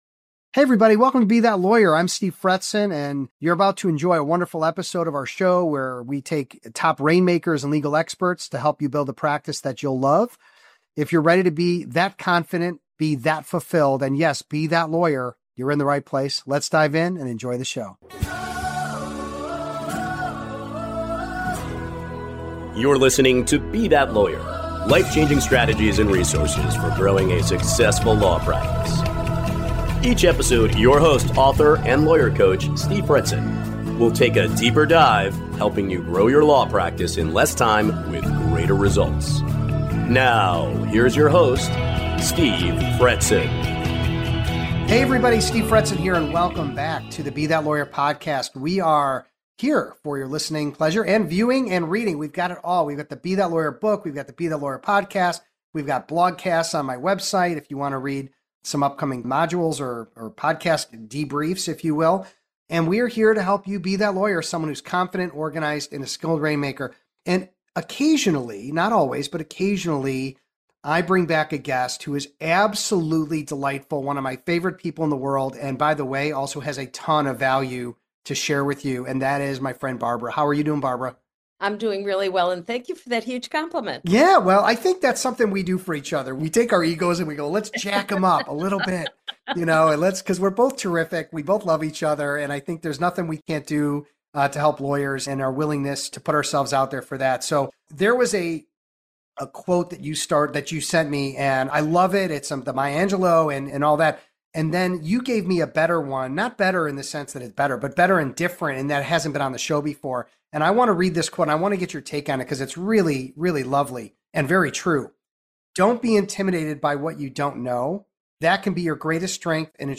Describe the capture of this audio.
1 218. Teaching Truths & Tactics: Live Lessons From Stanford in Capetown 34:36